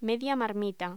Locución: Media marmita
voz